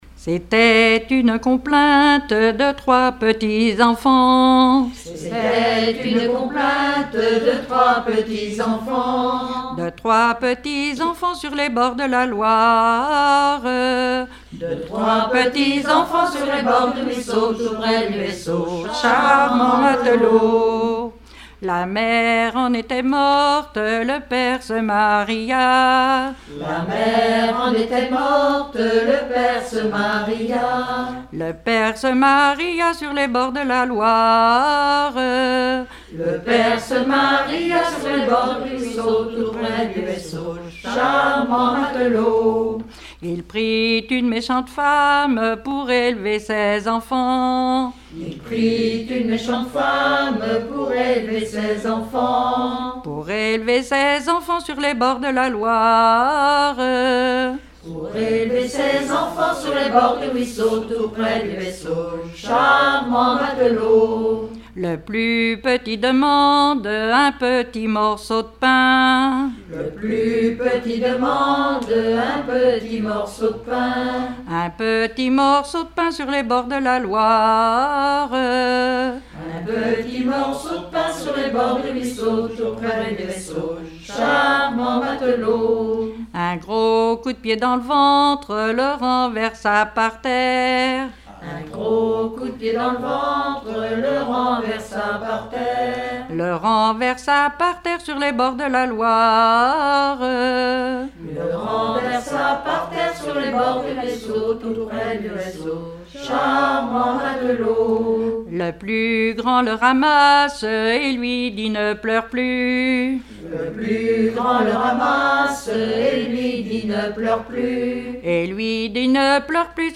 Genre strophique
Rassemblement de chanteurs
Pièce musicale inédite